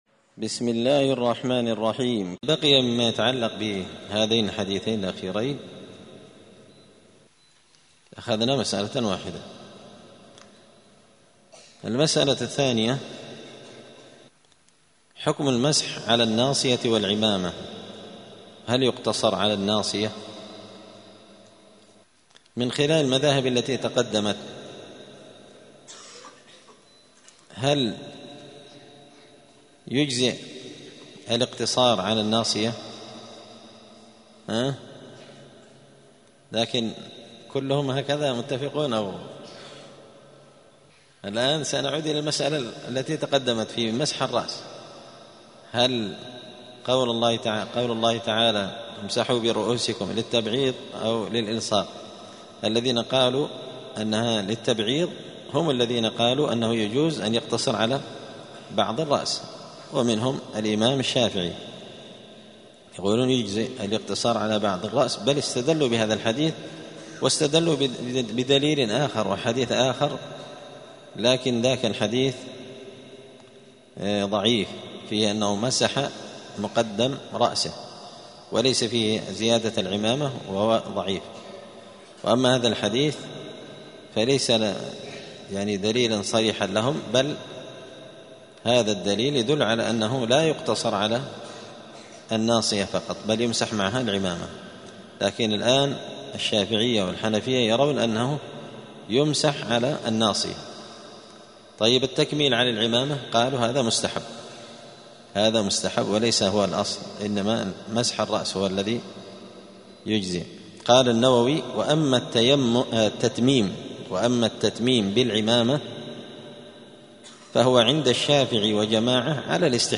دار الحديث السلفية بمسجد الفرقان قشن المهرة اليمن
*الدرس السابع والثلاثون [37] {باب صفة الوضوء عدد مرات الغسل في الوضوء}*